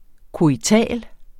Udtale [ koiˈtæˀl ]